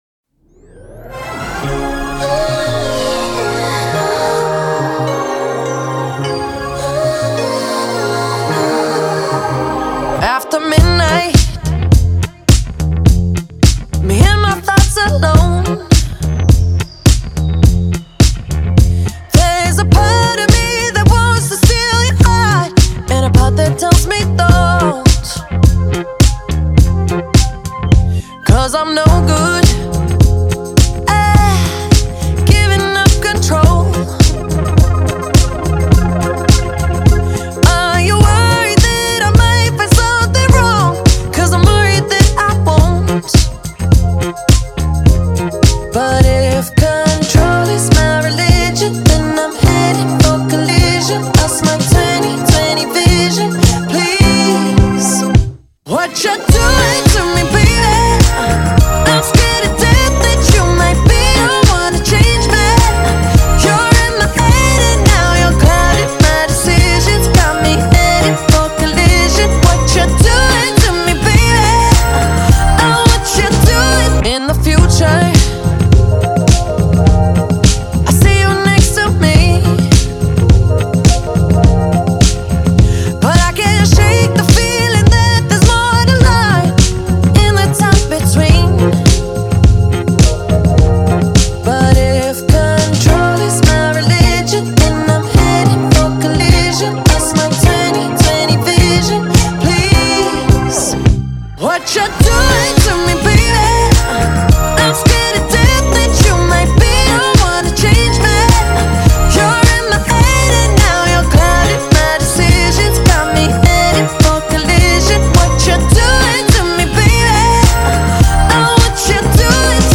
BPM105-105
Audio QualityPerfect (High Quality)
Full Length Song (not arcade length cut)